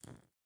Minecraft Version Minecraft Version latest Latest Release | Latest Snapshot latest / assets / minecraft / sounds / block / candle / ambient7.ogg Compare With Compare With Latest Release | Latest Snapshot